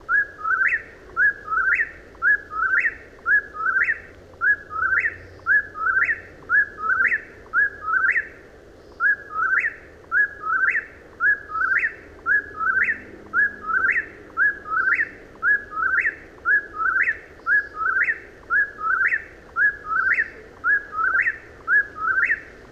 Whip-poor-will, whip-poor-will, whip-poor-will . . . .
Ah, here are better words to describe the call: Ethereal. Otherworldly.
whippoorwill-sound.wav